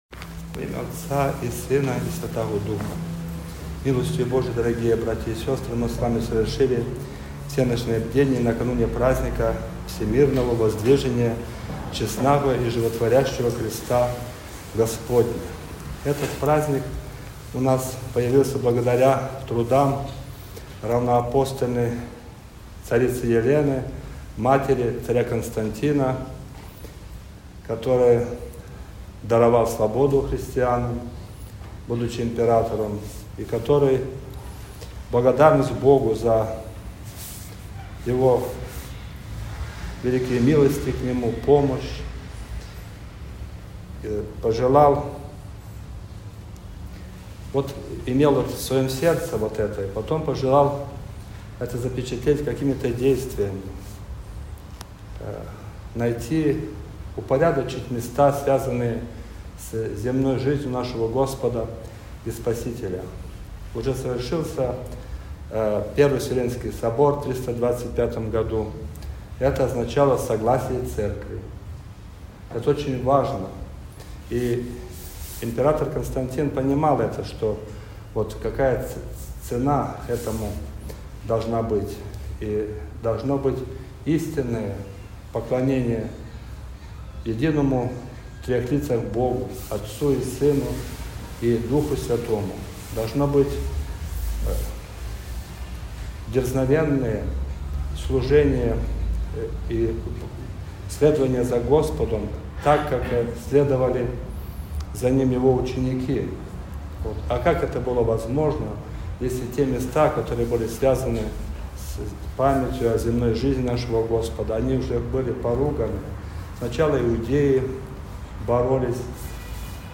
Проповедь
после Всенощного бдения
Всенощное-бдение.mp3